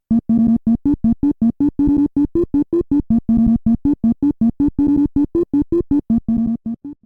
Converted to ogg and added a fade-out.